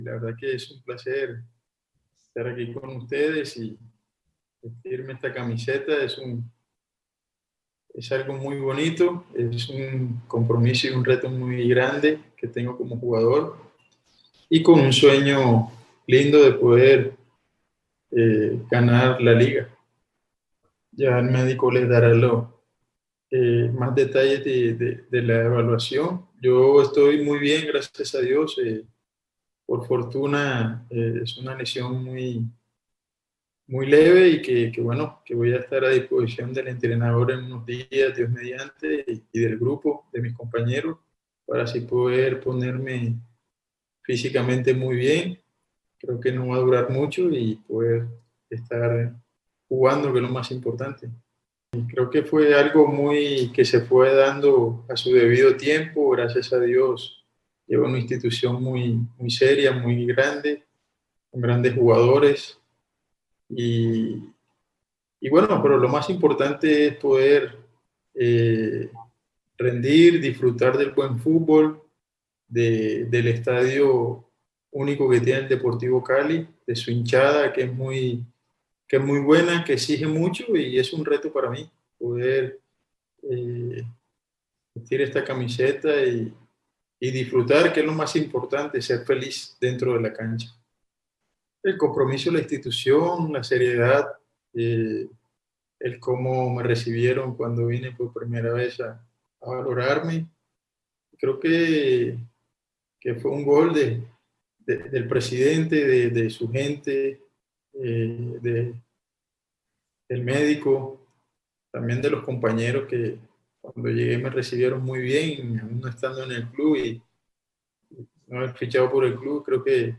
Teófilo Gutiérrez  en rueda de prensa